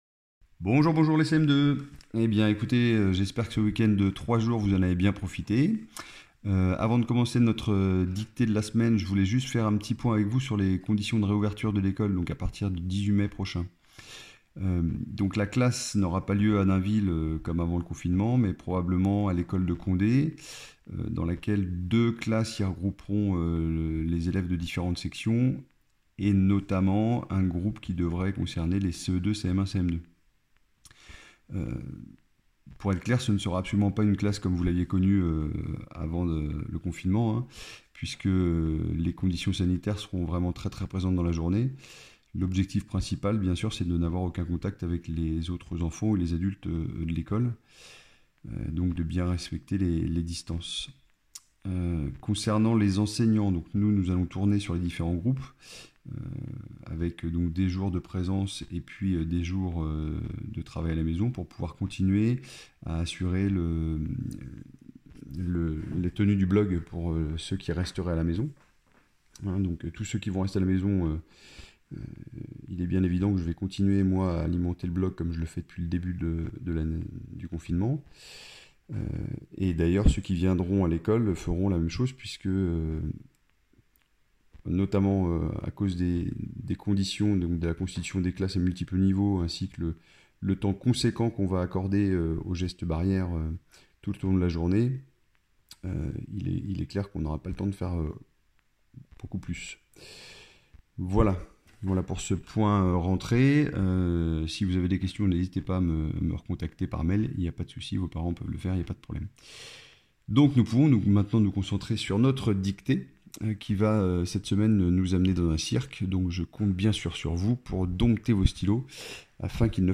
-Dictée :